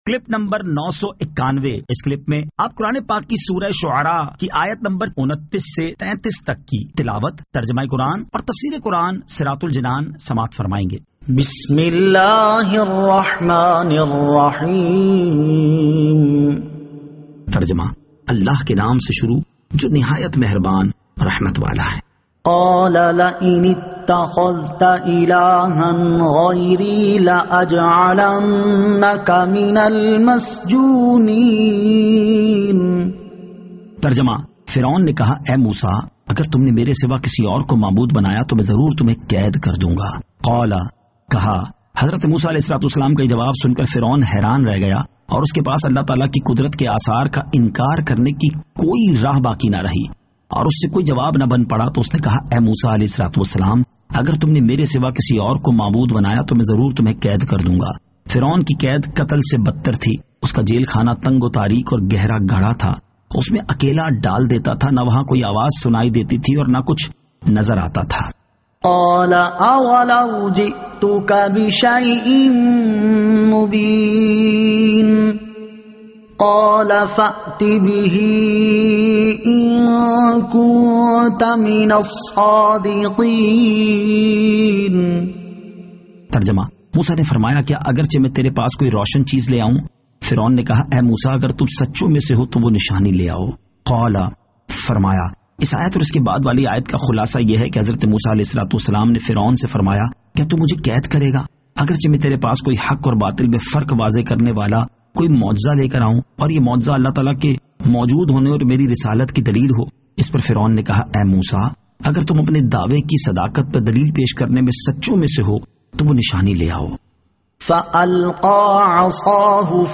Surah Ash-Shu'ara 29 To 33 Tilawat , Tarjama , Tafseer